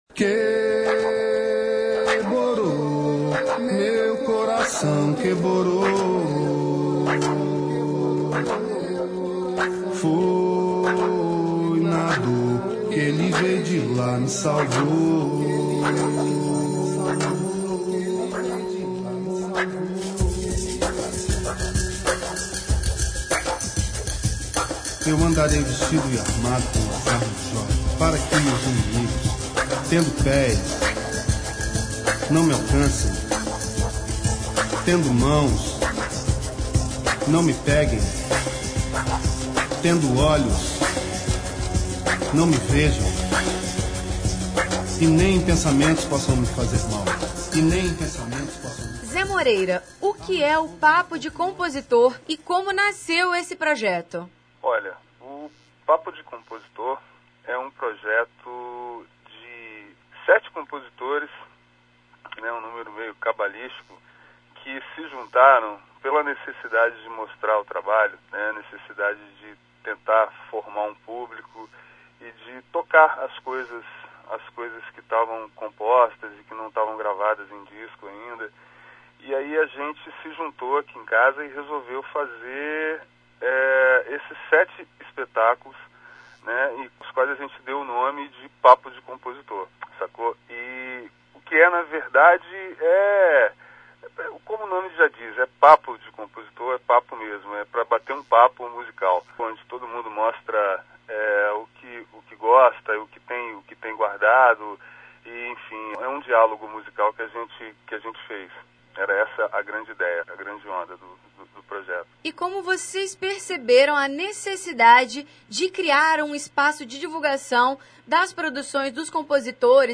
Confira a entrevista com o músico